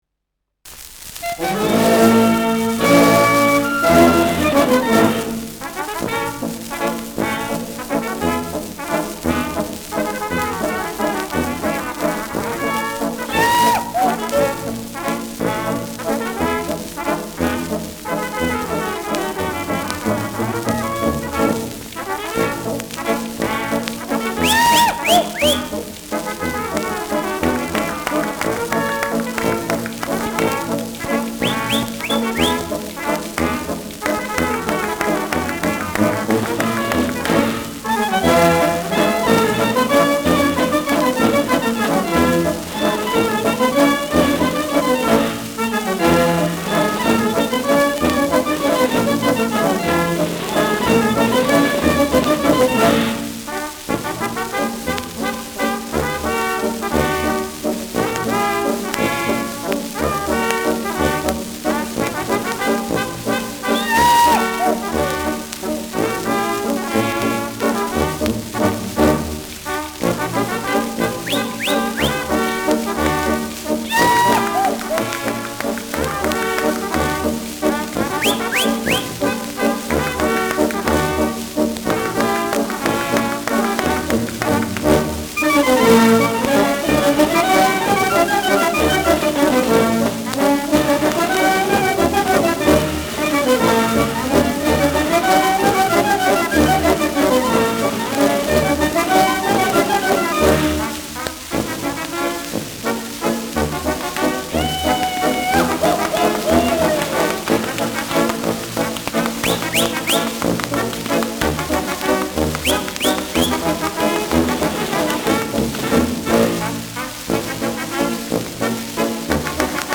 Schellackplatte
präsentes Rauschen : leichtes Knacken bei 0’58’’ : leichtes Knistern
Kapelle Moar (Interpretation)
Juchzer, Pfiffe, Klopfgeräusche, Ausruf am Ende.
[Berlin] (Aufnahmeort)